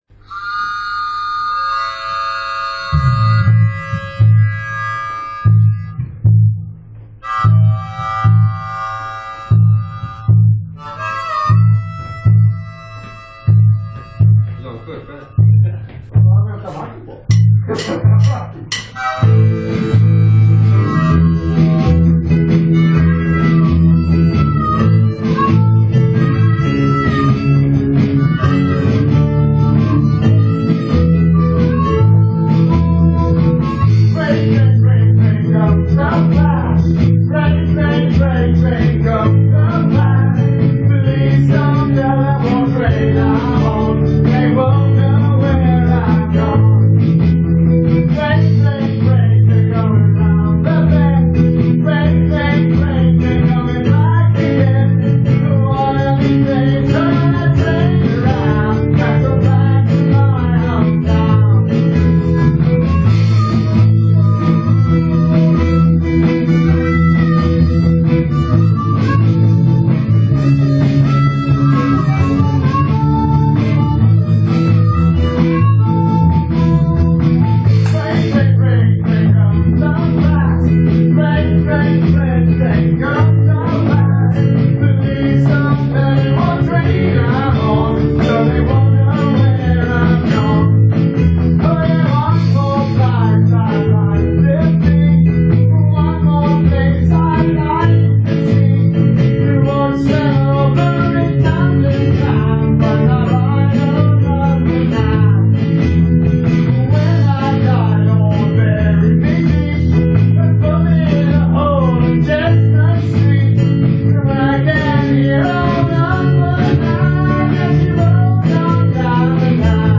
Bandet
Repetition på Chalmers 2015-04-19